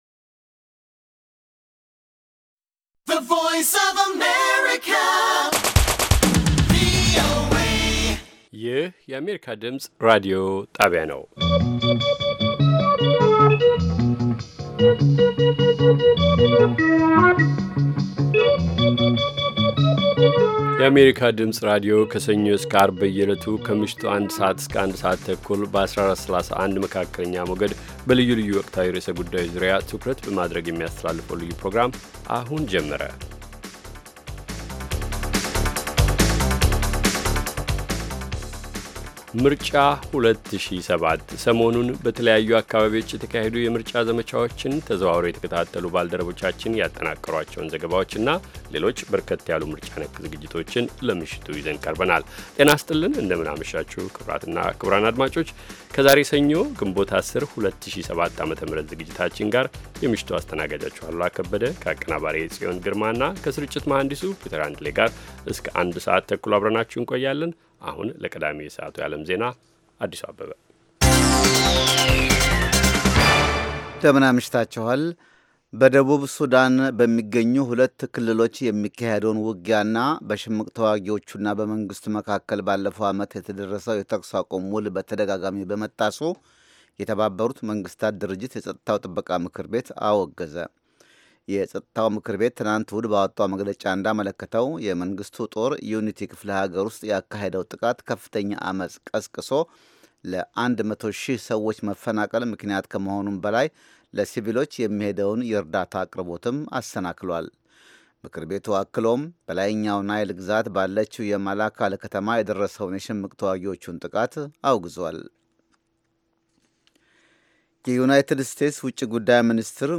ከምሽቱ ኣንድ ሰዓት የአማርኛ ዜና